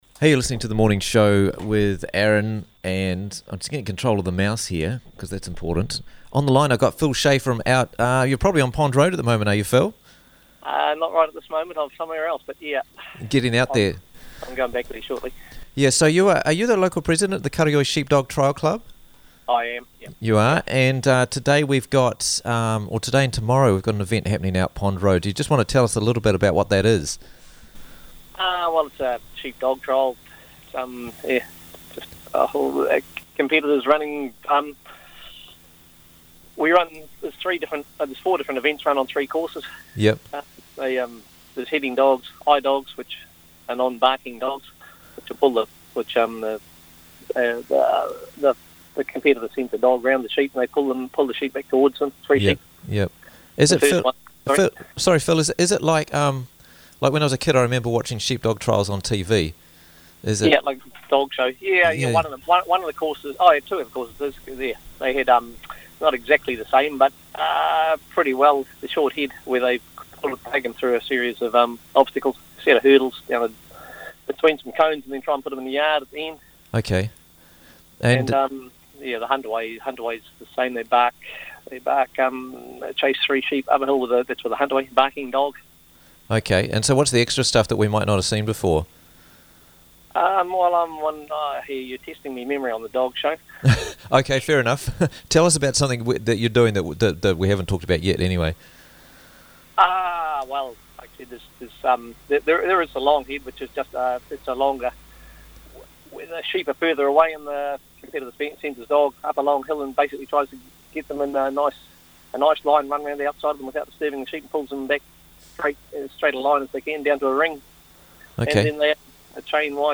Karioi Dog Trials Friday & Saturday - Interviews from the Raglan Morning Show